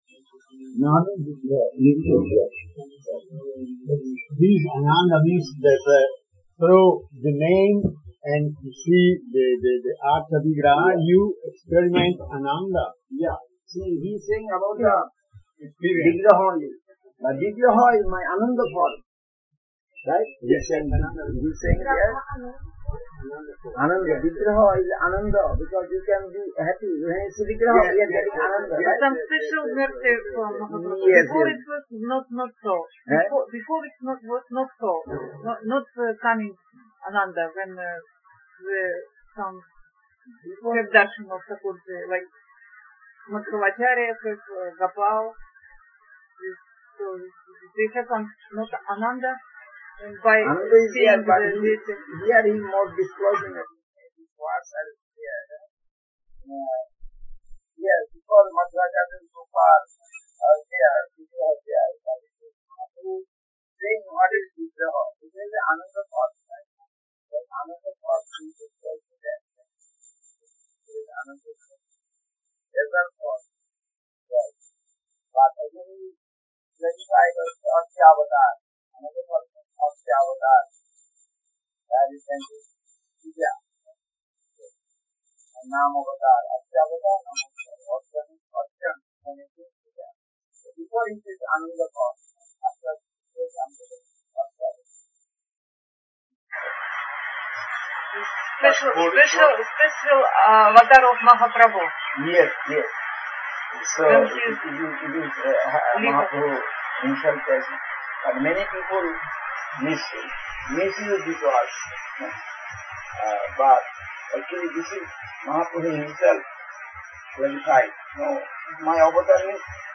India, SREE CAITANYA SRIDHAR SEVA ASHRAM, Nabadwip.